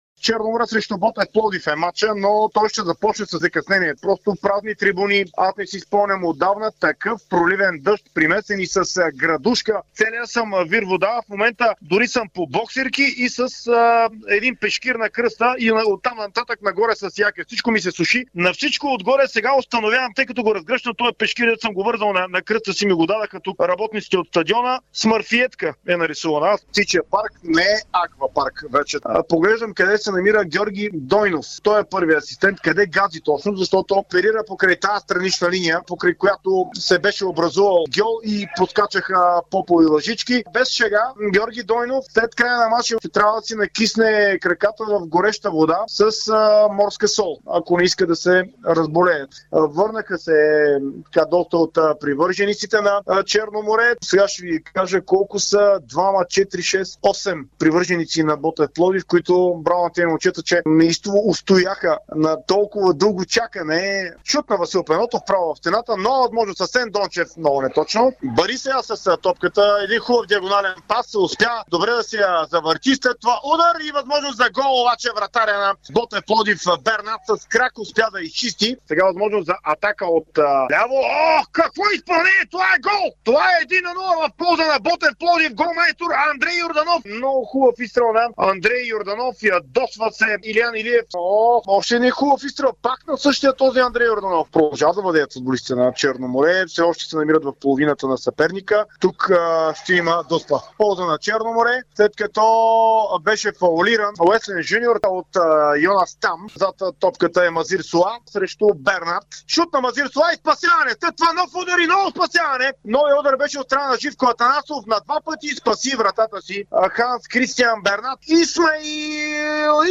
Какво чухме по Дарик радио от родните футболни терени от изминалия 28-и кръг в Първа лига, който ни предложи проливен дъжд, приказен пешкир, попови лъжички на „Тича“, ледени голове и Коледа посред април, през очите на коментаторите на Дарик на двубоите.